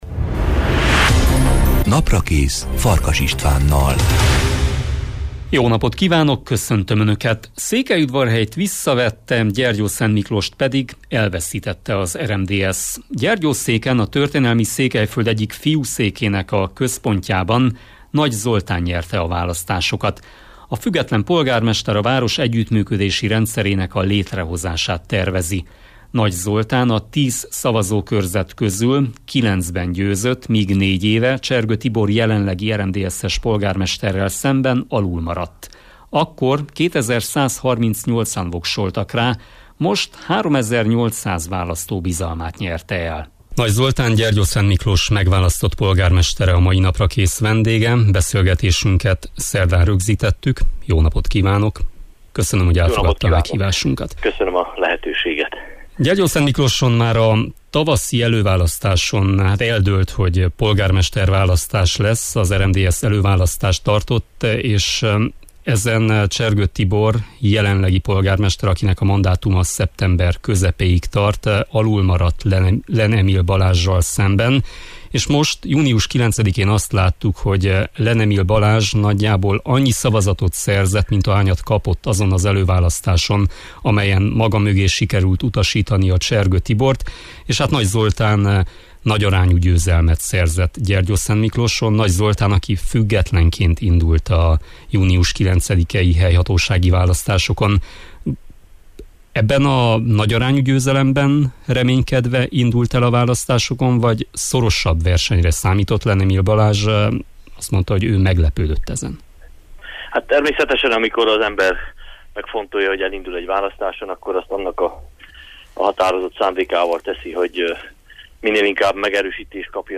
Nagy Zoltán a vendégem.